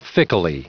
Prononciation du mot fickly en anglais (fichier audio)